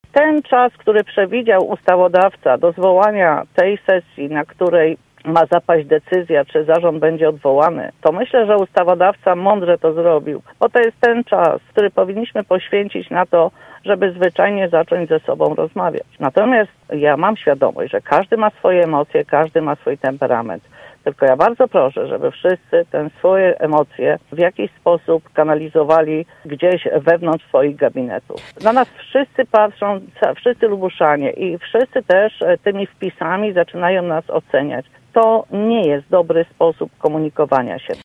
Wioleta Haręźlak była gościem Rozmowy Punkt 9.